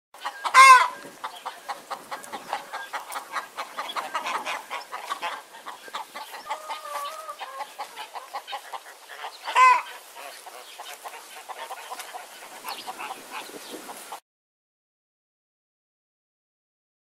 جلوه های صوتی
دانلود صدای مرغ 2 از ساعد نیوز با لینک مستقیم و کیفیت بالا